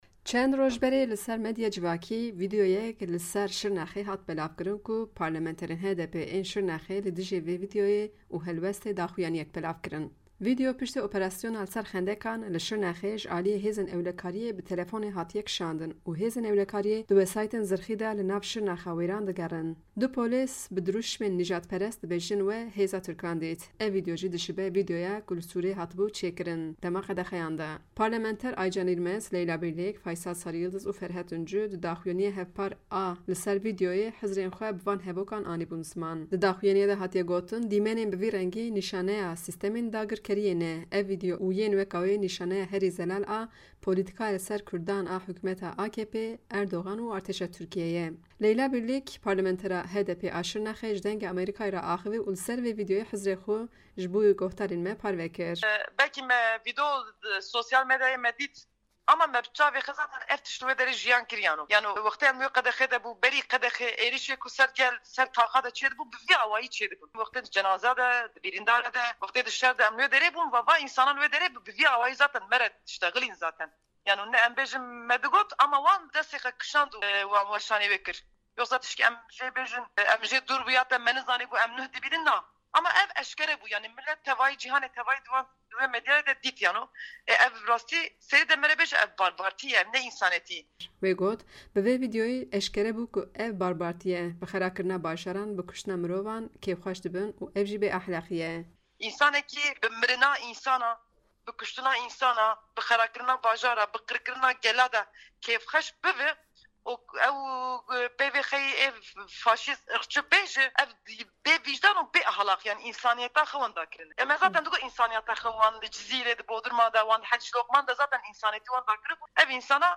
Leyla Bîrlîk ji Dengê Amerîka re axifî û li ser vê vîdyoyê nêrînên xwe parve kir.